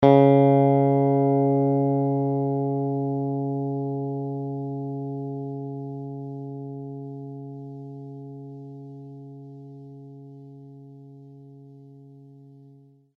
bass-electric
Added sound samples